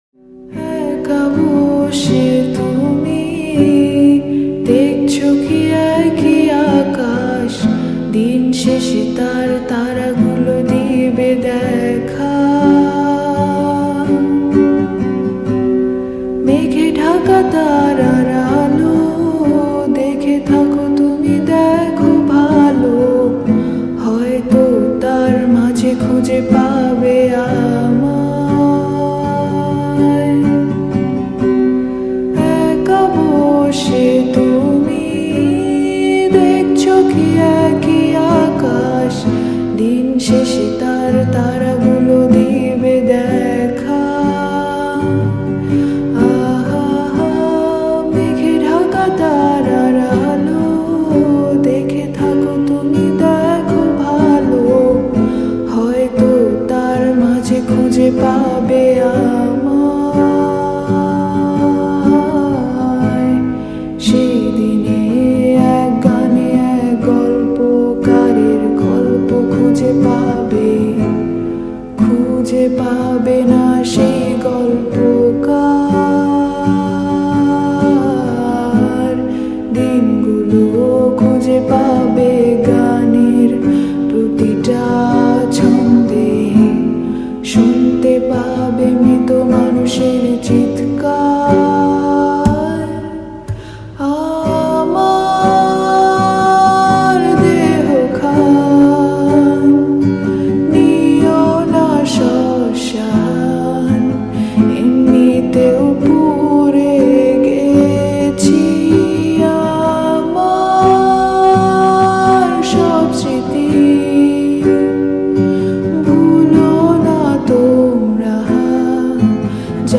Slowed And Reverb Bangla New Lofi Song